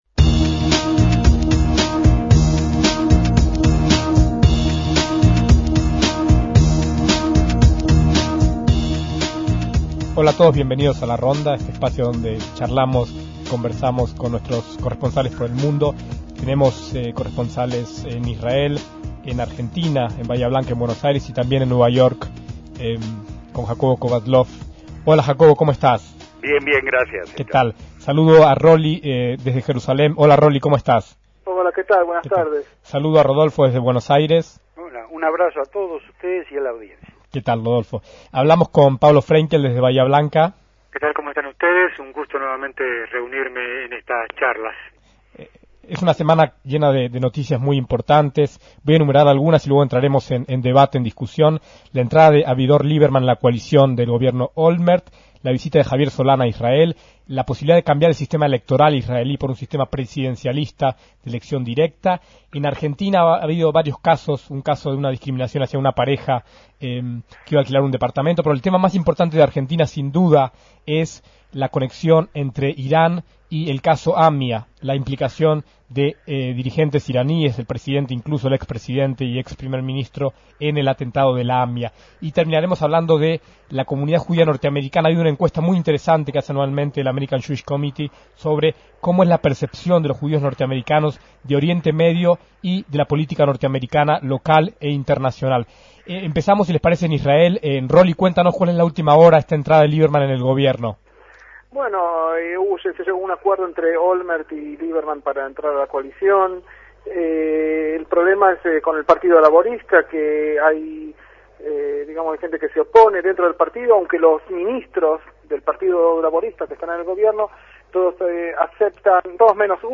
En esta ronda de corresponsales de 2006